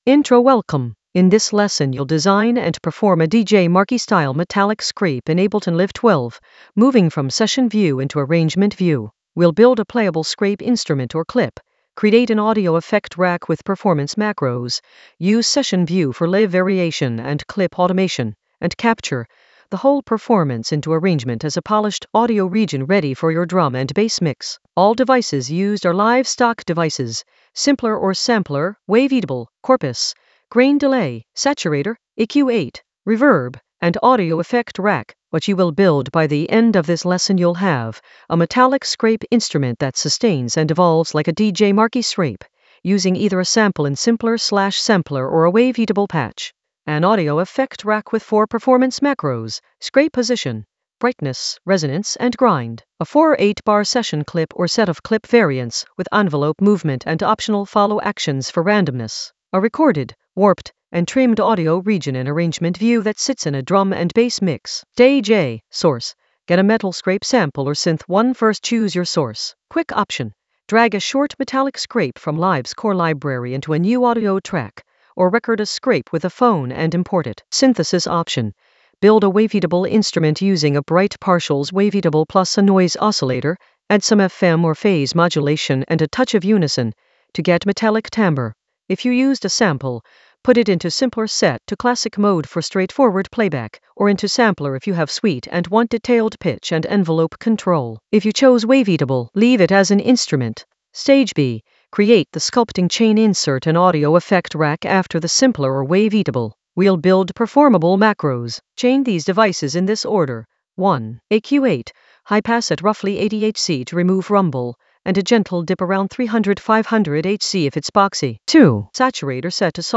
An AI-generated intermediate Ableton lesson focused on DJ Marky metal scrape in Ableton Live 12 using Session View to Arrangement View in the Drums area of drum and bass production.
Narrated lesson audio
The voice track includes the tutorial plus extra teacher commentary.